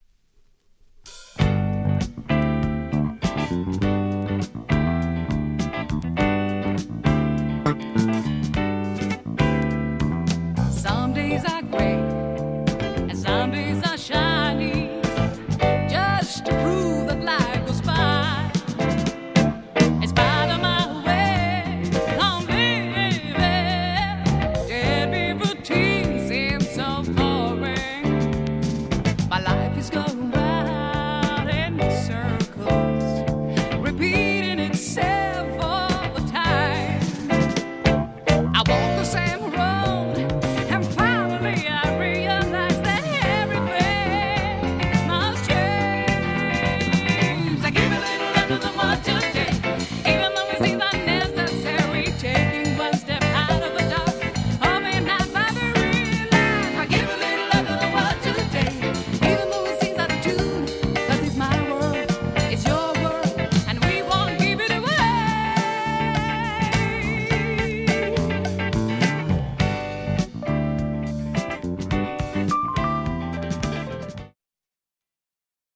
ジャジーなアレンジとポップな要素が融合したジャズ〜ブラジリアン〜ポップ〜ロックにまで幅広く愛される素晴らしいアルバム。